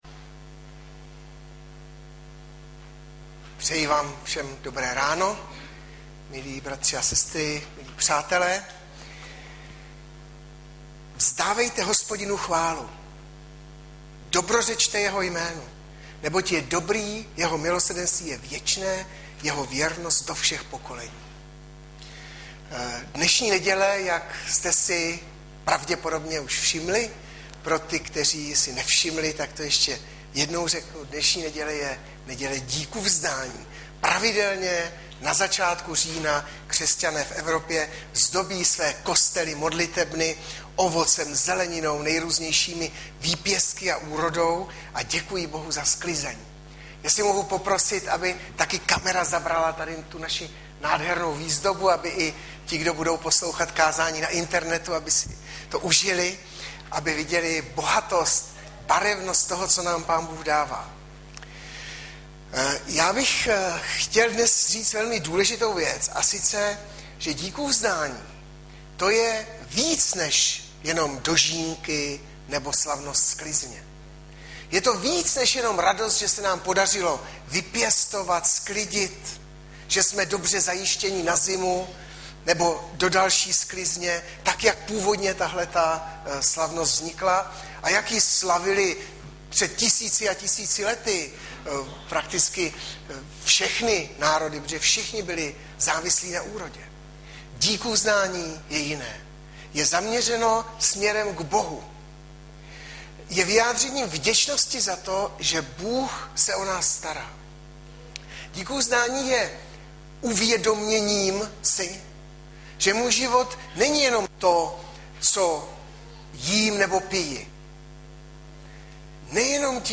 Hlavní nabídka Kázání Chvály Kalendář Knihovna Kontakt Pro přihlášené O nás Partneři Zpravodaj Přihlásit se Zavřít Jméno Heslo Pamatuj si mě  14.10.2012 - DÍKUVZDÁNÍ - Žalm 100 Audiozáznam kázání si můžete také uložit do PC na tomto odkazu.